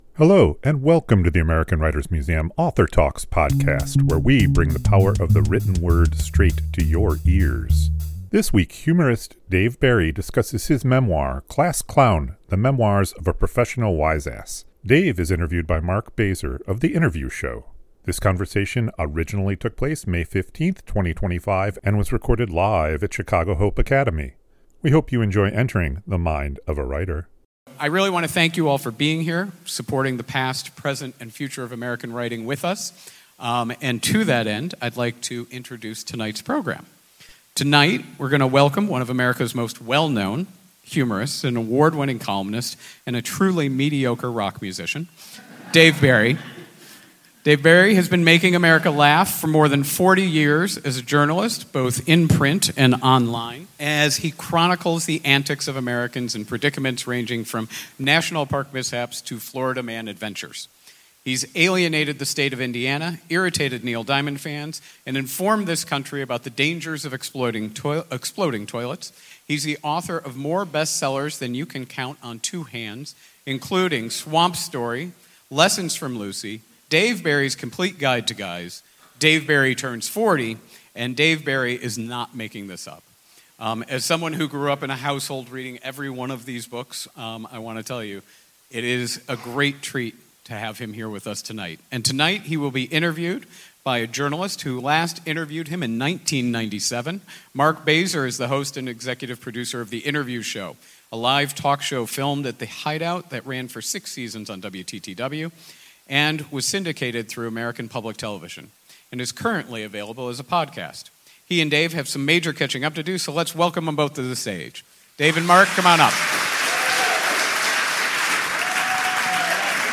This conversation originally took place May 15, 2025 and was recorded live at Chicago Hope Academy.